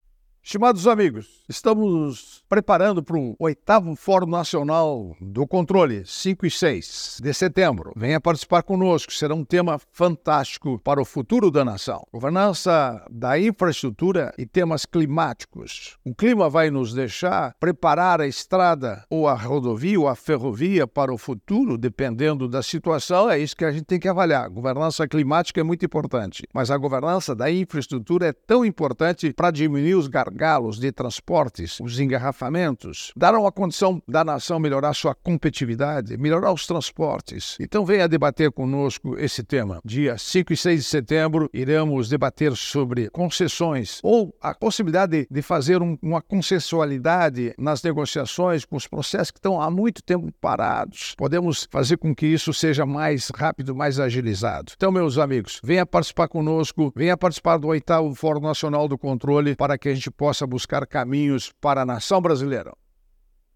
É o assunto do comentário desta quarta-feira (04/09/24) do ministro Augusto Nardes (TCU), especialmente para OgazeteitO.